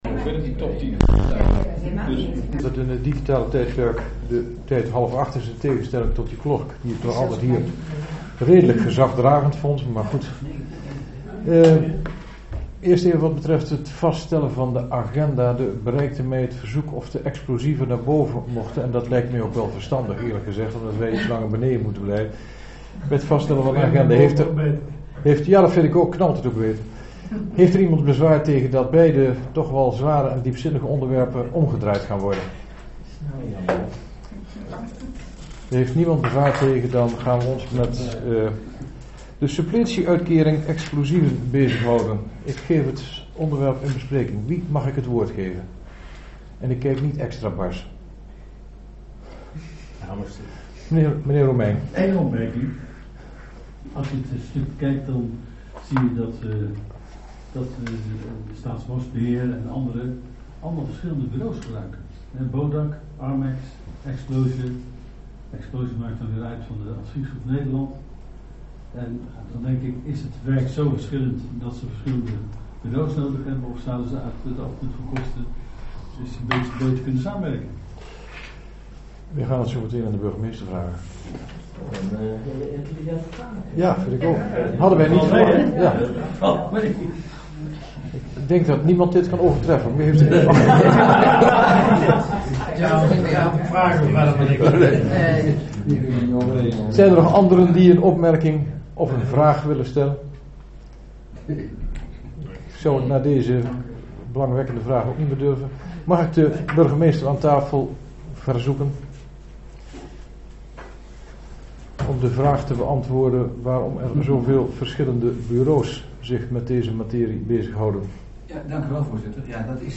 Locatie gemeentehuis Elst Voorzitter dhr. G.J.M. op de Weegh Toelichting Voorbereidende vergadering nota bovenwijkse voorzieningen Voorbereidende vergadering Aanvraag suppletie uitkering explosieven 2017 Agenda documenten 18-01-23 opname 3.